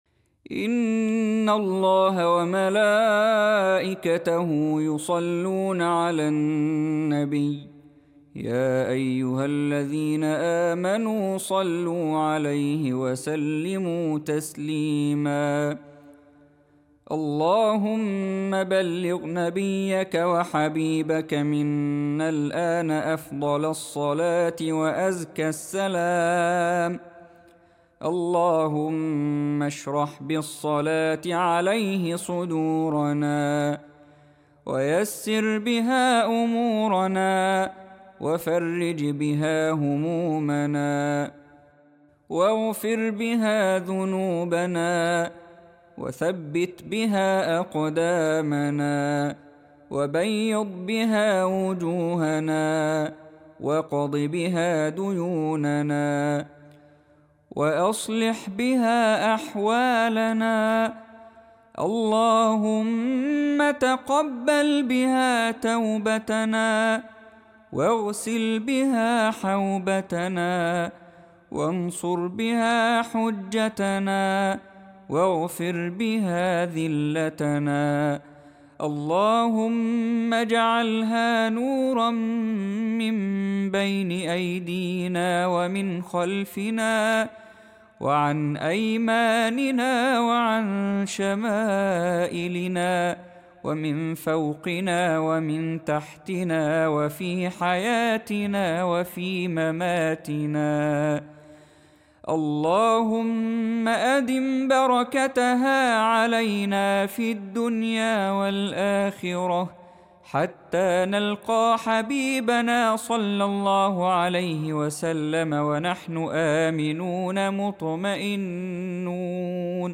دعاء مؤثر مليء بالأنس والاشتياق، يركز على الصلاة على النبي محمد صلى الله عليه وسلم وطلب البركة والتيسير في جميع شؤون الحياة. يدعو الله أن يجعل هذه الصلاة نوراً وفرجاً، وينتهي برجاء اللقاء بالنبي في الآخرة بكل أمن وطمأنينة.